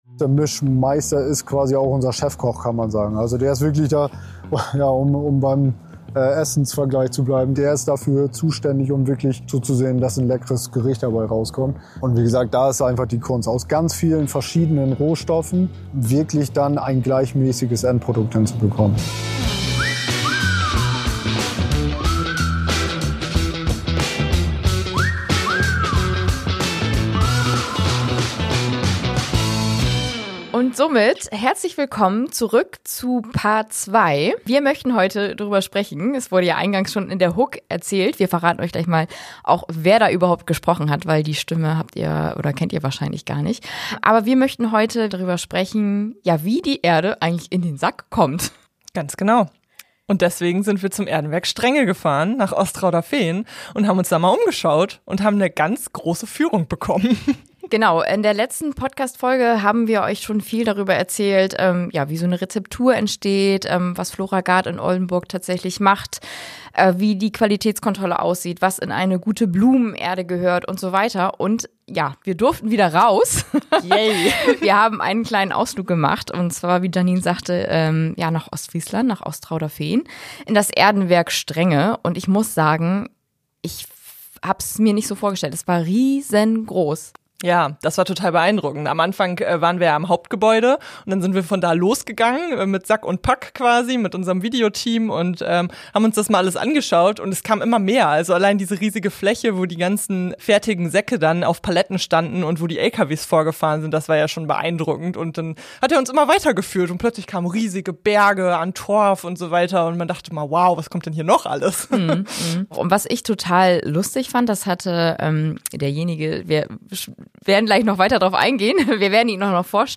werfen vor Ort einen Blick hinter die Kulissen eines modernen Erdenwerks in Ostfriesland.